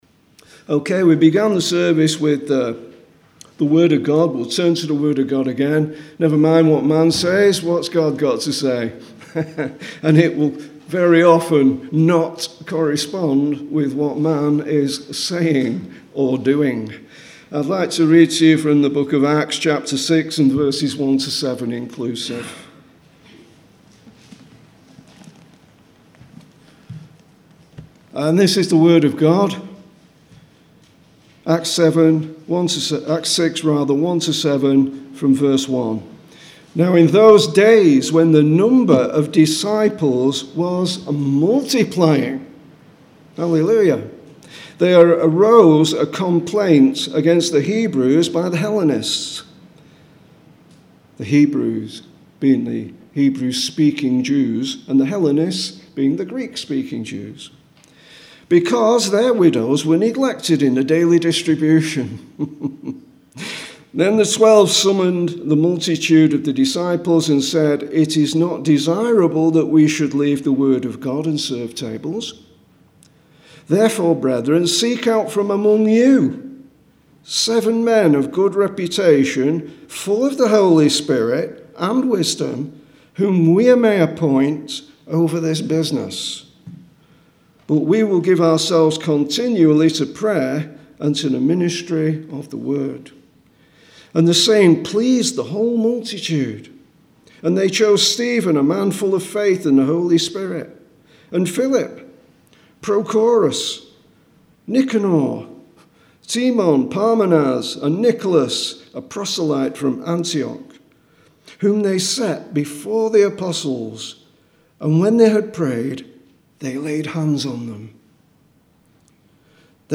Sermons from Kirkhall Lane Independent Methodist Church